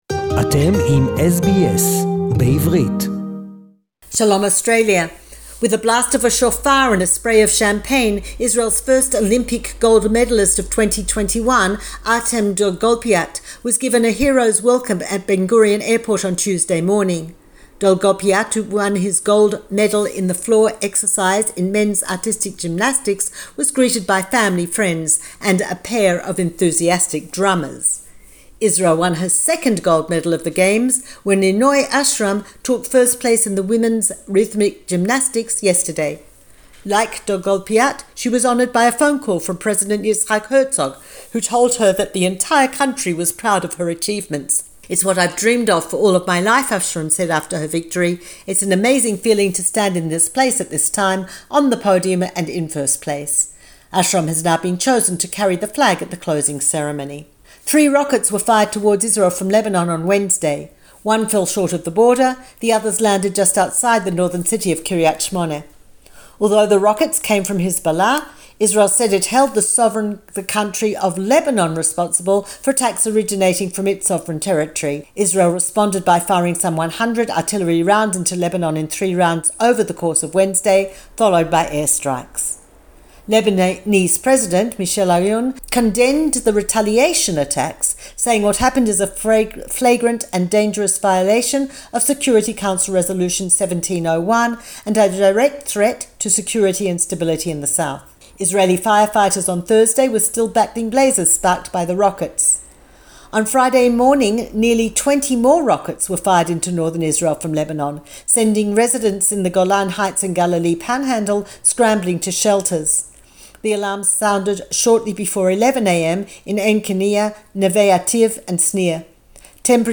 SBS Jerusalem report in English